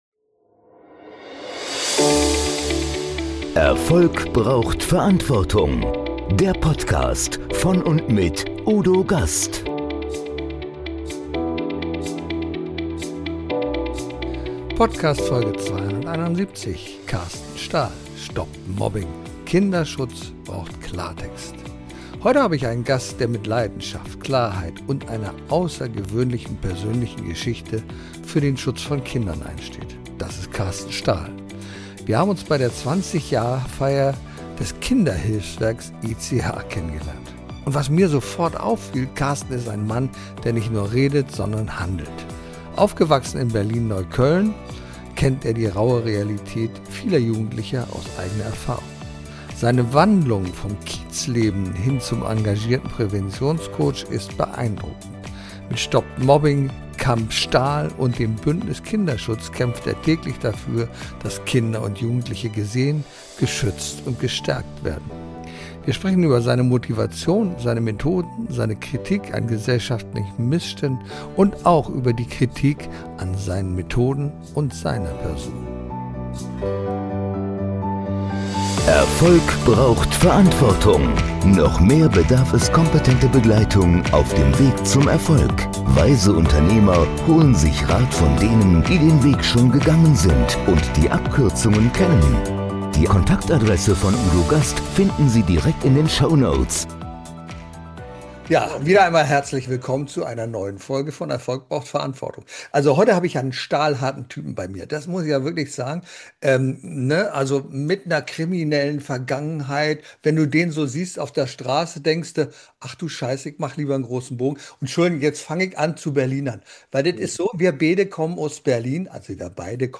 Beschreibung vor 4 Monaten Heute habe ich einen Gast, der mit Leidenschaft, Klarheit und einer außergewöhnlichen persönlichen Geschichte für den Schutz von Kindern einsteht: Carsten Stahl.